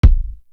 Bang Bang Kick.wav